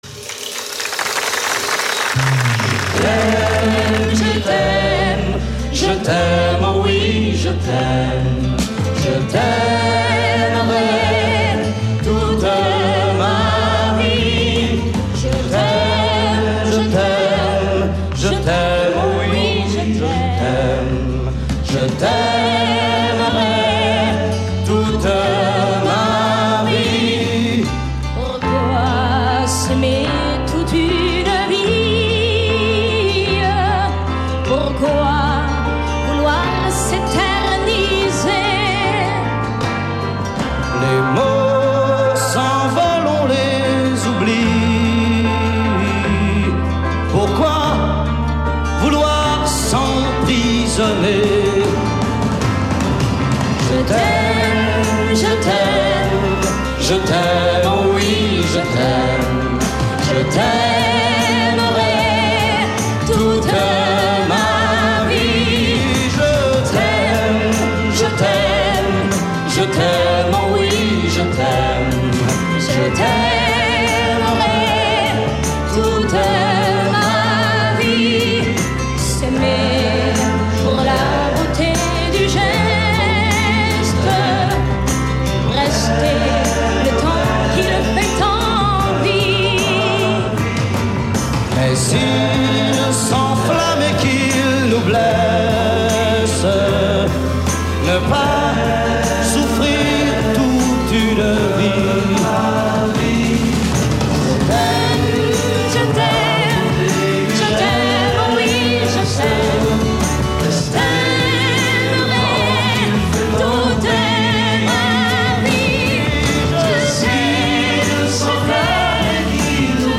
Versions TV